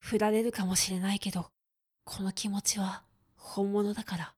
クール男性
dansei_hurarerukamoshirenaikedokonokimotihahonmonodakara.mp3